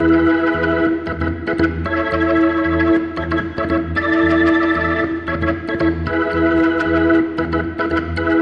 优秀的60年代风琴曲
描述：上世纪60年代末风格的欢快的哈蒙德风琴旋律。